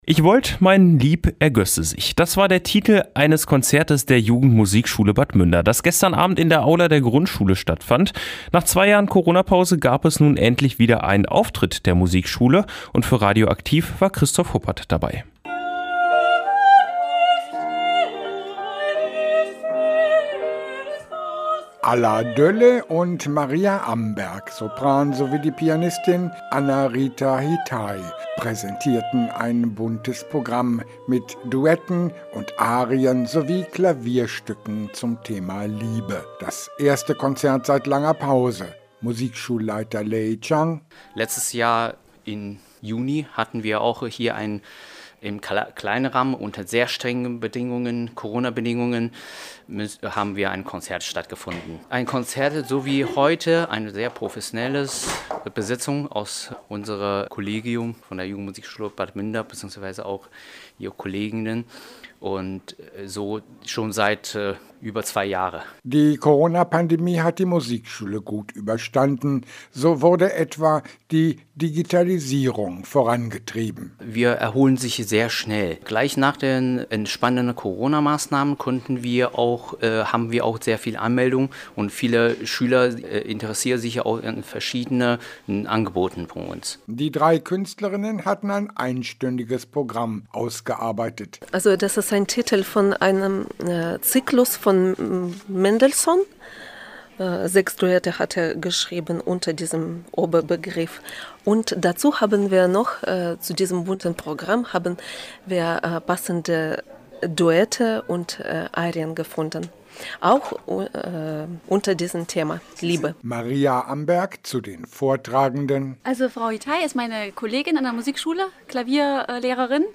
Mit einem professionellen Konzert von drei Lehrkräften konnte sich die Jugendmusikschule Bad Münder nach zwei Jahren wieder in der Öffentlichkeit präsentieren.